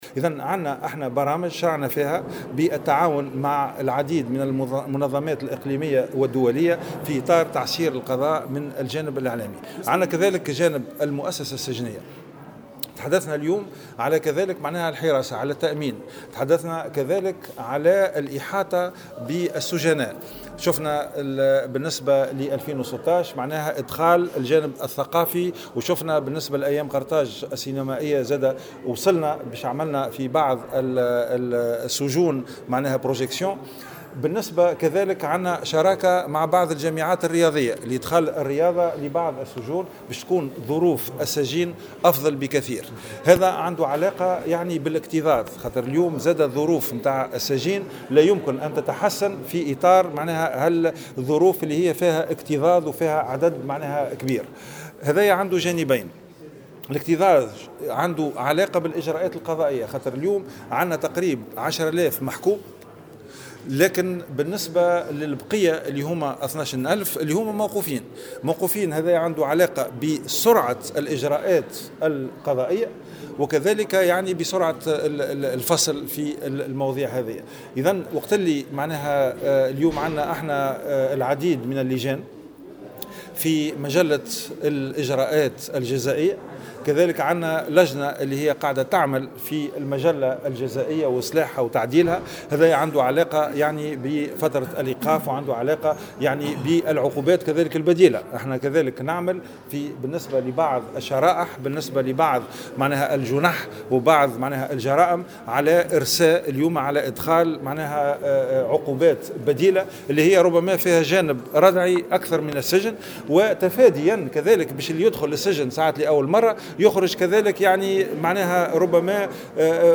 أكد وزير العدل غازي الجريبي، في تصريح لمراسل الجوهرة أف أم، على هامش أثناء مناقشة ميزانية وزارته من قبل لجنة التشريع العام ، اليوم الأربعاء، أن الاعتمادات المرصودة لوزارته ستخصص في جانب منها لتأمين المحاكم على مستوى الحراسة والمراقبة، وصيانتها وإحداث محاكم جديدة وتعصير المنظومة الإعلامية القضائية.